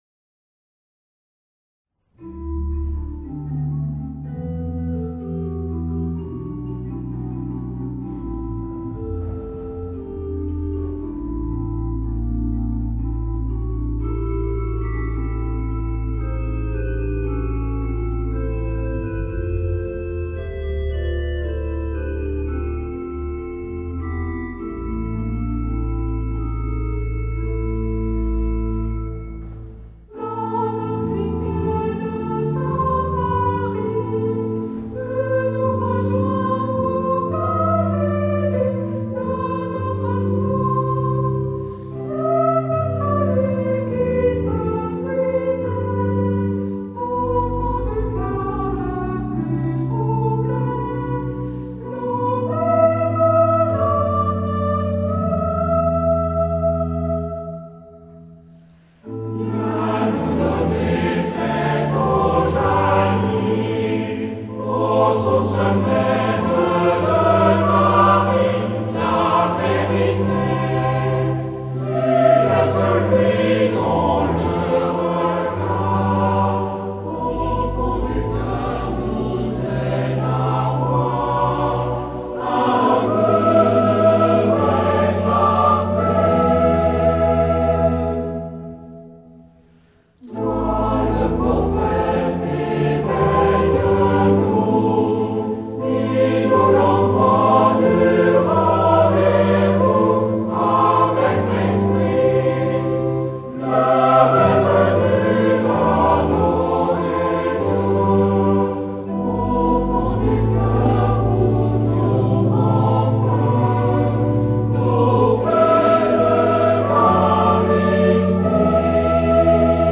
Genre-Stil-Form: geistlich ; Hymnus (geistlich)
Charakter des Stückes: ruhig
Chorgattung: SATB  (4-stimmiger gemischter Chor )
Instrumente: Orgel (1)
Tonart(en): a-moll